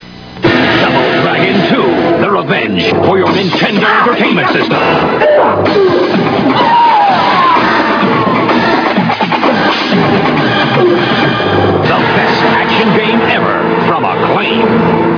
This ad for Double Dragon 2: The Revenge is a good example. Instead of some hyperactive teenager, this ad is done in extremely horribly-drawn animation, complete with silly karate-chop sound effects, all while the announcer claims that it's "The best action game ever!".